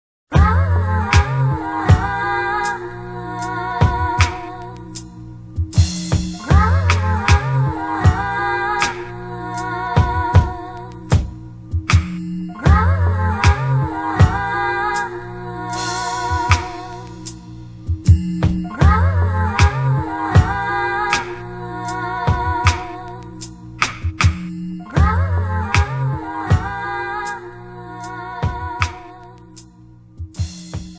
5 Styl: Hip-Hop Rok